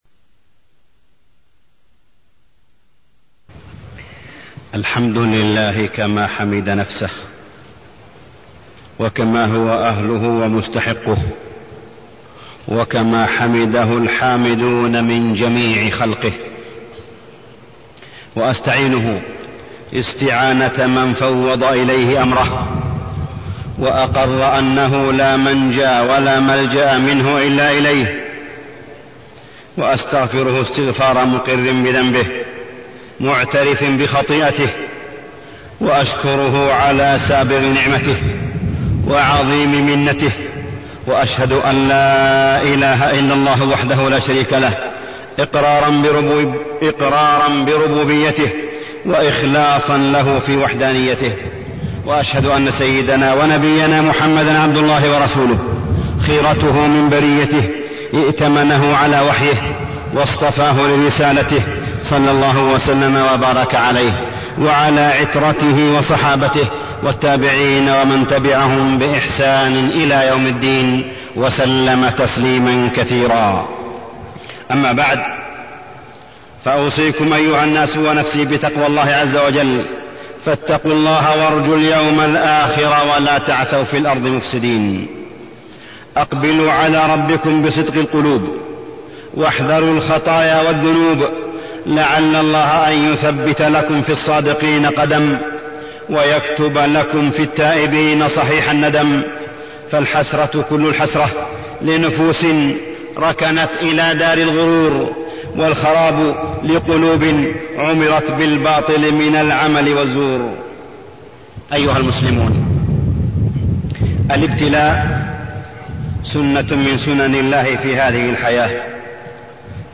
تاريخ النشر ١٨ رجب ١٤٢١ هـ المكان: المسجد الحرام الشيخ: معالي الشيخ أ.د. صالح بن عبدالله بن حميد معالي الشيخ أ.د. صالح بن عبدالله بن حميد قضية الإرهاب